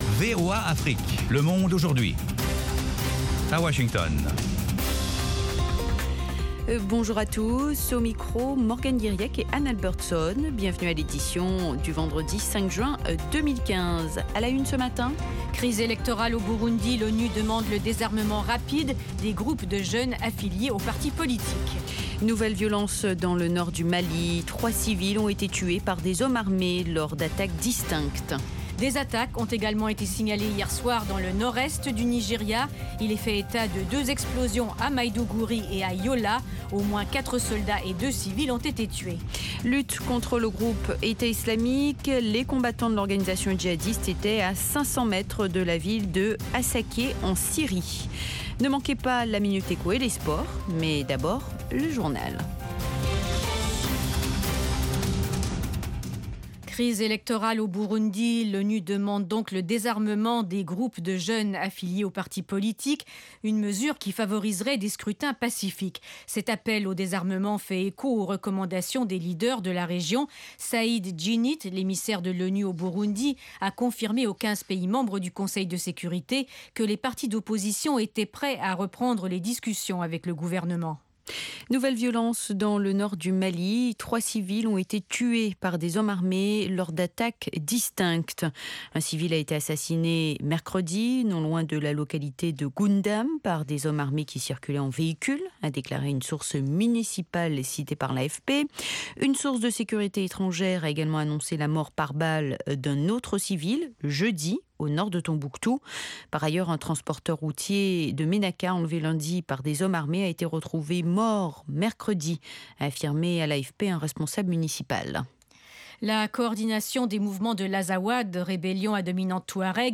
Toute l’actualité sous-régionale sous la forme de reportages et d’interviews.
Le Monde aujourd'hui, édition pour l'Afrique de l’Ouest, c'est aussi la parole aux auditeurs pour commenter à chaud les sujets qui leur tiennent à coeur.